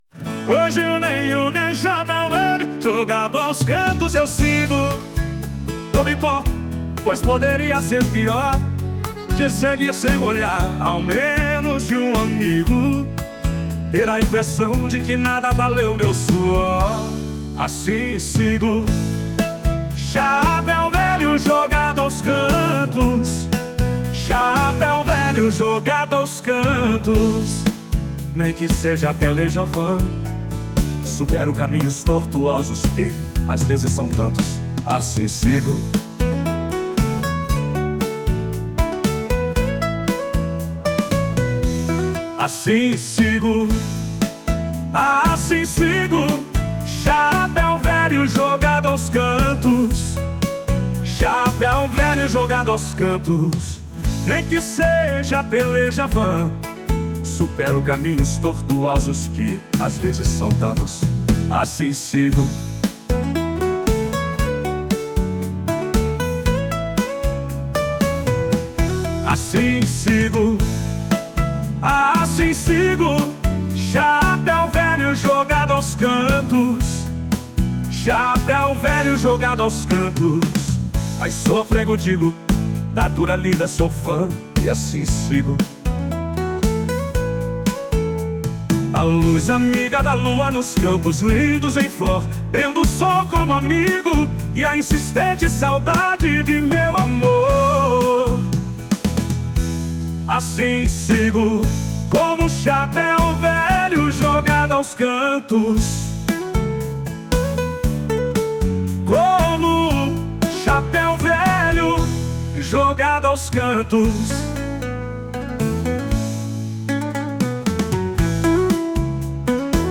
[voz masculina]